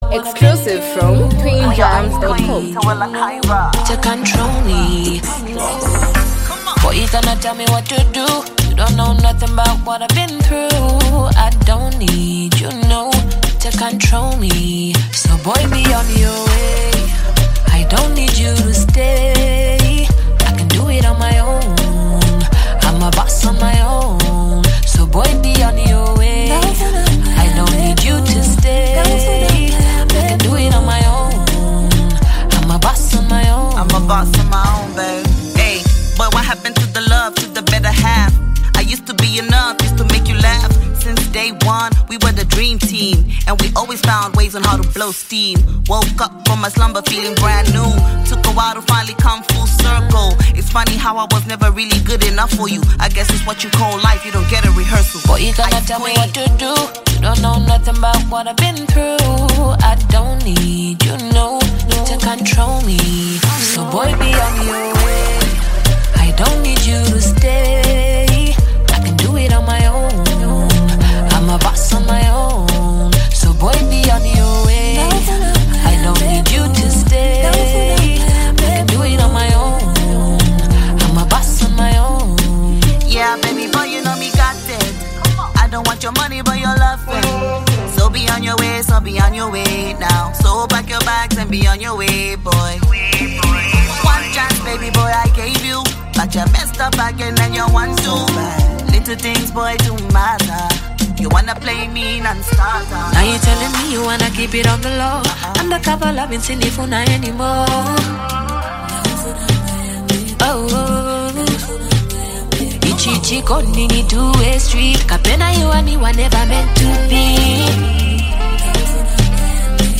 is a reflective and emotionally charged song
smooth and soulful vocals